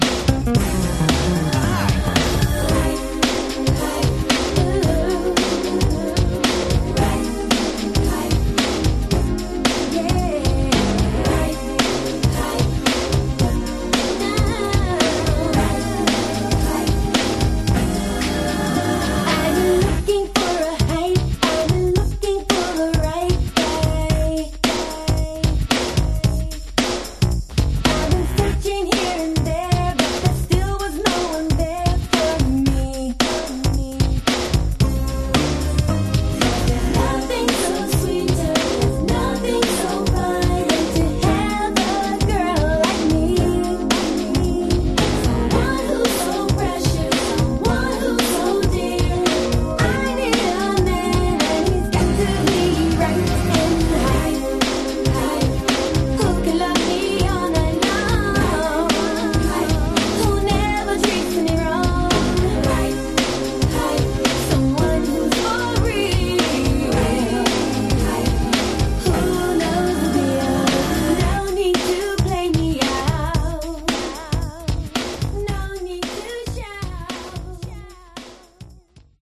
Genre: Funk/Hip-Hop/Go-Go
This female dance group had only one hit, but it's a doozy!